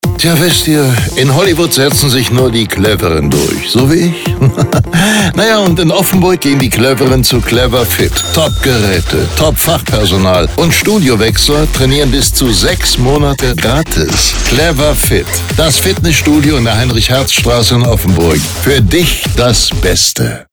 Dabei setzen wir konsequent auf echte Sprecherinnen und Sprecher – keine KI-Stimmen.
Motivierend, kraftvoll, zielgerichtet.
Clever-Fit-Image-Musik-3-20s.mp3